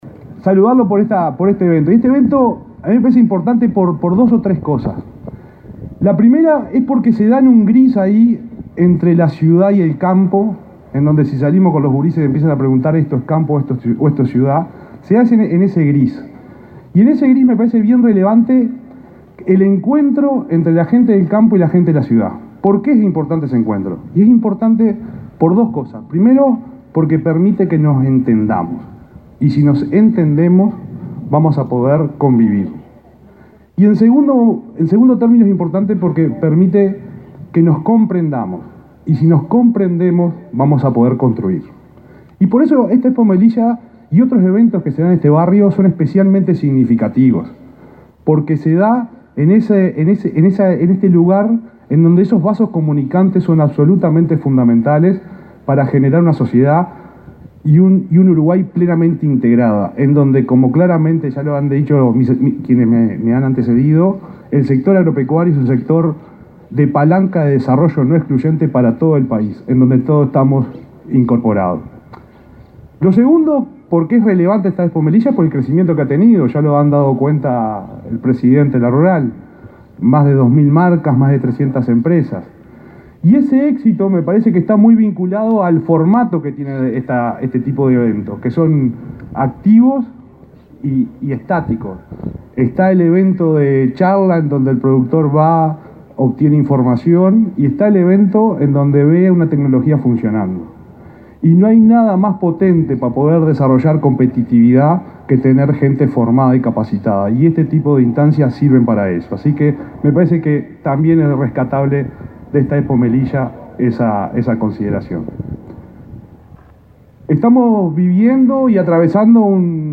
Palabras del ministro interino de Ganadería, Ignacio Buffa
El ministro interino de Ganadería, Ignacio Buffa, participó en la inauguración de la Expo Melilla 2023.